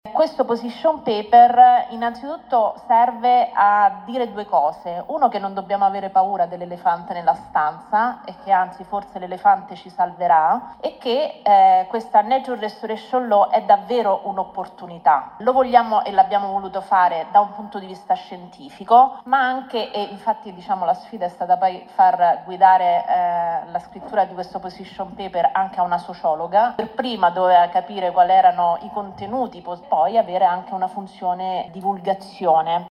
Festival dello Sviluppo Sostenibile - Presentato a Genova il Position Paper dell’ASviS sull’attuazione in Italia del Regolamento per il ripristino della natura. Ascoltiamo la relatrice Rossella Muroni.